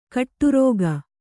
♪ kaṭṭurōga